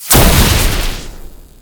hit.ogg